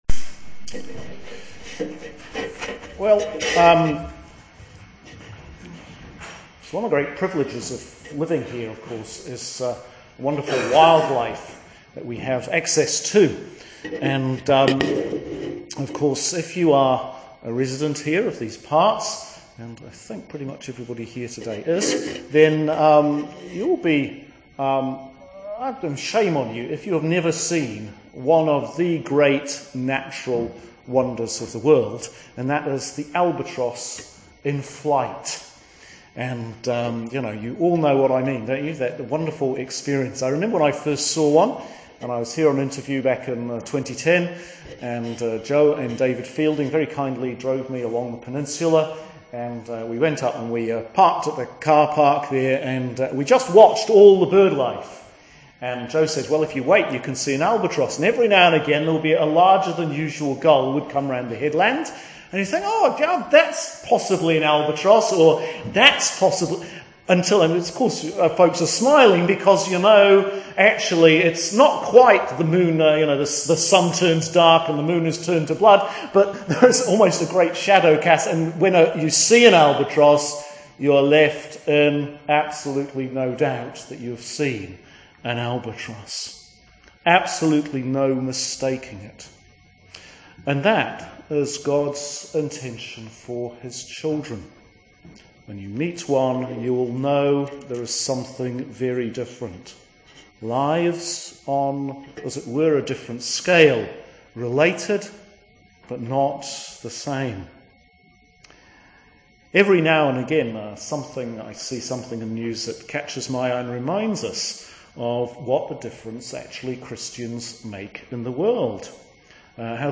Sermon for Christmas 2 – Sunday January 5th, 2014, Year A